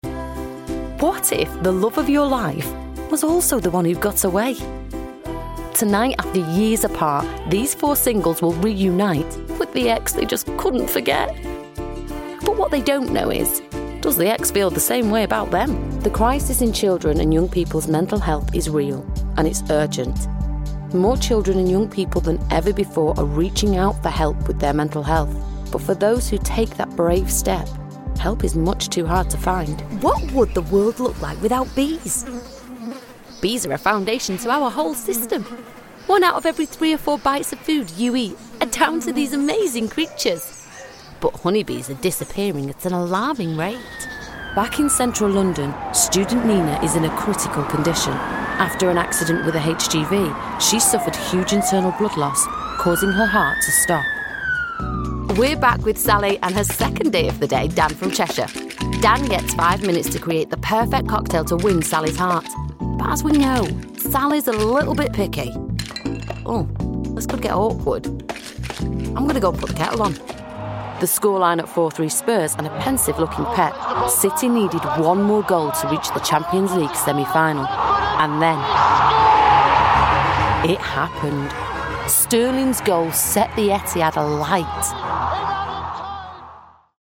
British Female Voice over with 16 years experience and a Professional studio.
Warm, engaging and conversational
Sprechprobe: Sonstiges (Muttersprache):